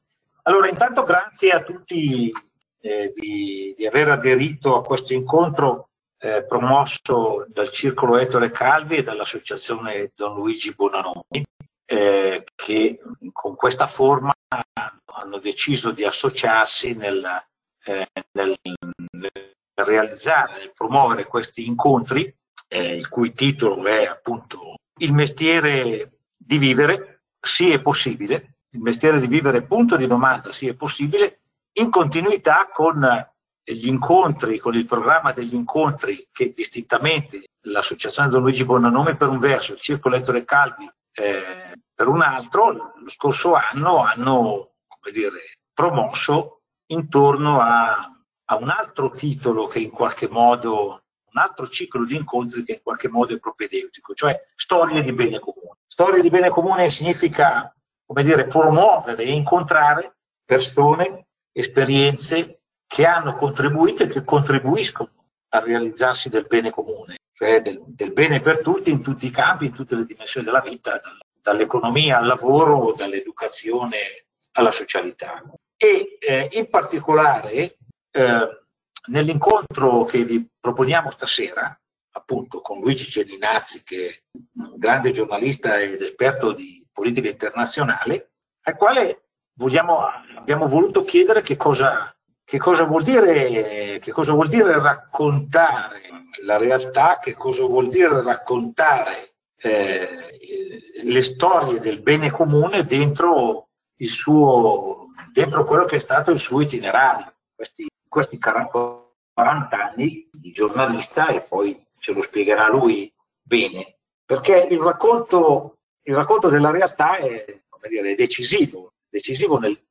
(*) l’incontro si è svolto in modalità videoconferenza: abbiamo avuto cura di eliminare le imperfezioni o le interruzioni che possono essersi verificati nel corso della registrazione a causa della perdita di connessione. Vogliate perdonare eventuali difetti ancora presenti.